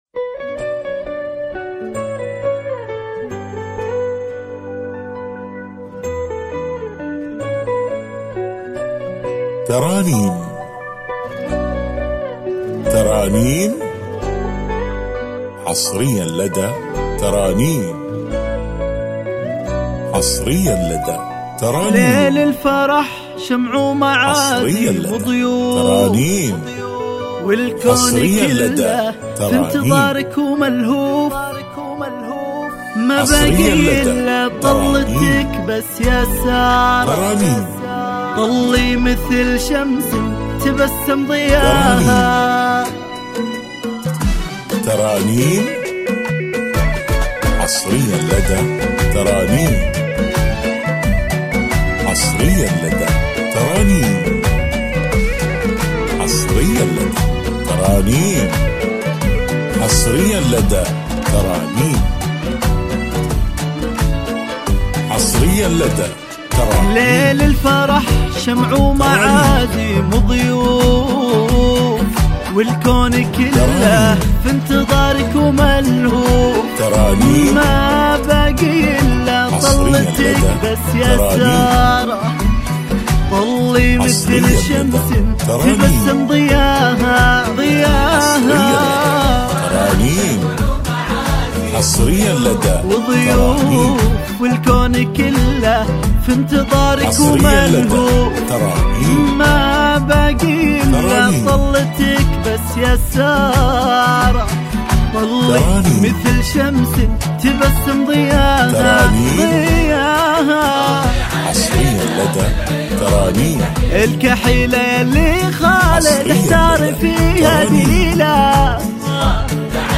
زفات 2026